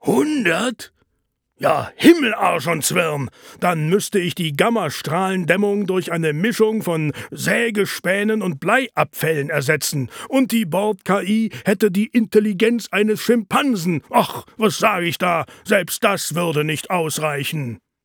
Sprachbeispiel des Erfinders Edward Peach (Detlef Bierstedt) Version